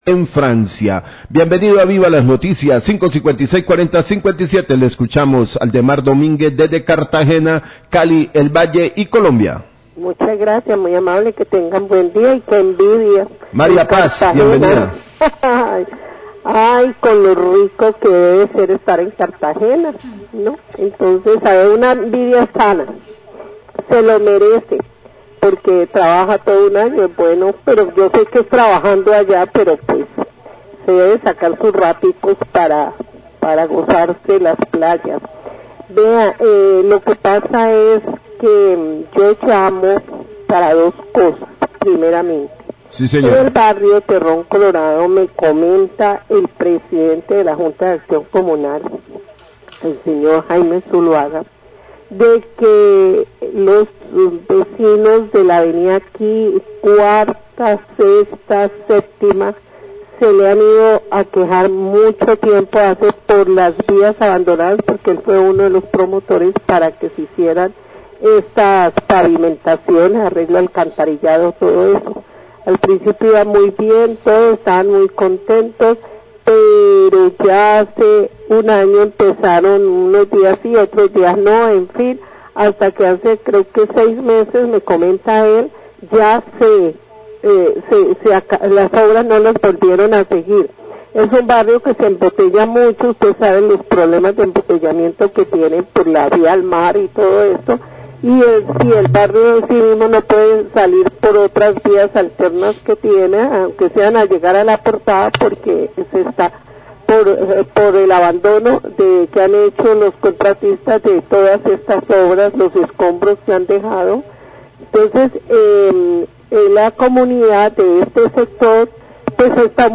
Radio
Oyente manifiesta que la comunidad de Terrón Colorado realizará este jueves un plantón en la vía al mar, como protesta por incumplimiento de obras de alcantarillado y pavimentación de las calles.